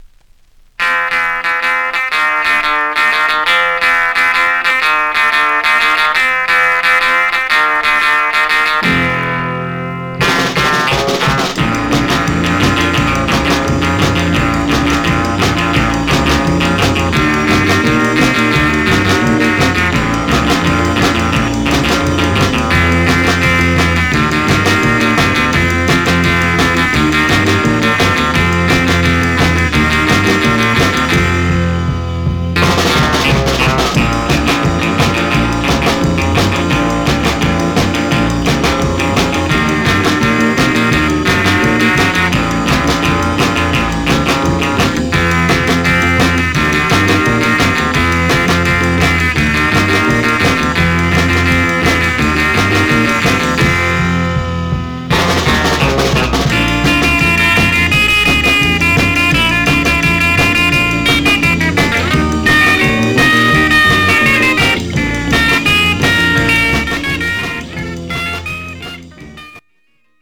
Stereo/mono Mono
R & R Instrumental Condition